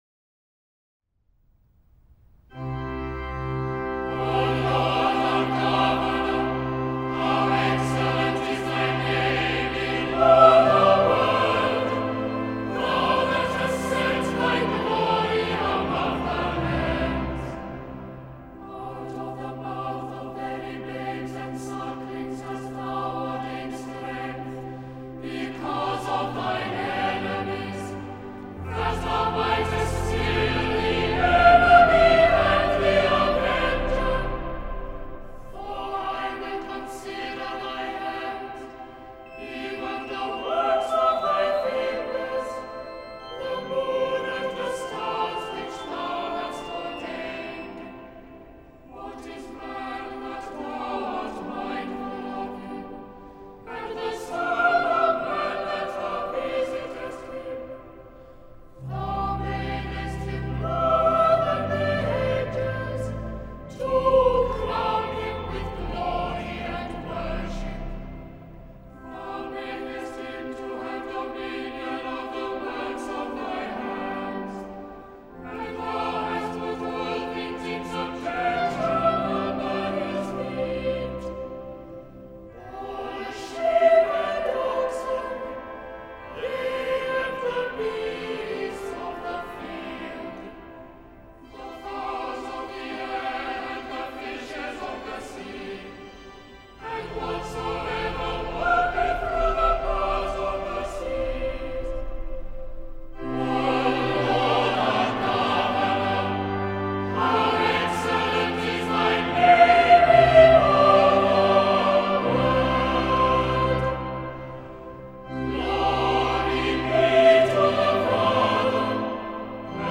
Listen to the Choir of Westminster Abbey perform a setting after Henry Lawes of Psalm 8.